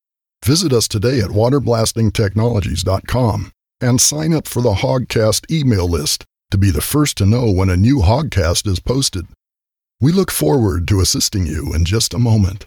Male
Adult (30-50), Older Sound (50+)
a deep baritone voice with some grit and a tone of wisdom, authority, warmth and trust
Short Sample Of On-Hold Message